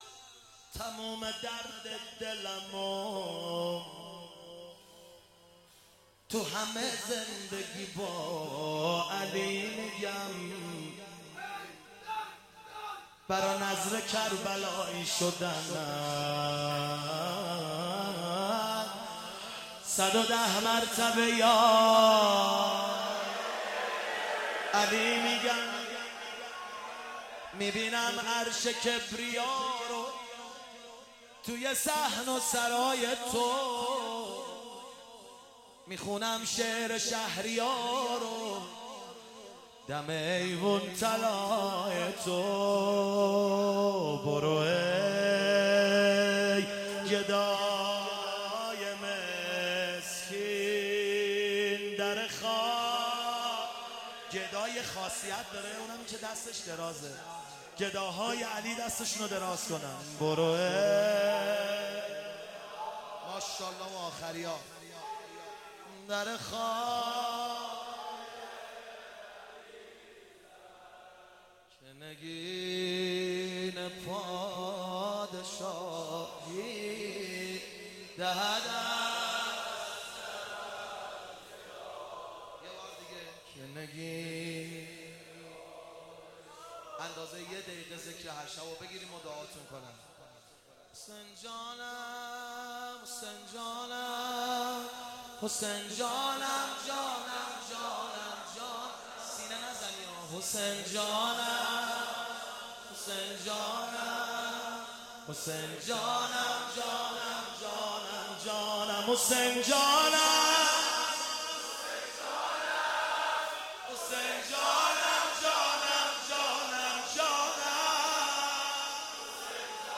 روضه پایانی